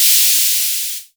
Index of /musicradar/essential-drumkit-samples/Vintage Drumbox Kit
Vintage Cymbal 01.wav